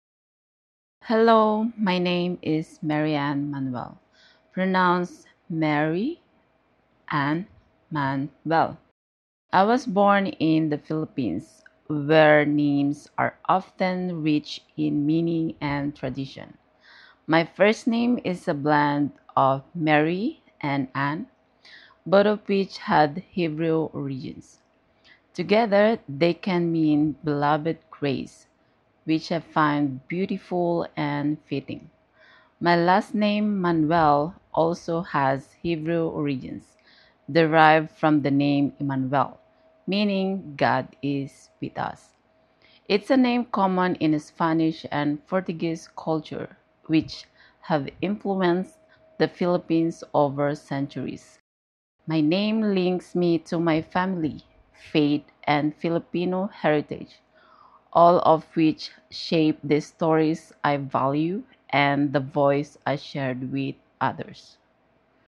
Audio Name Pronunciation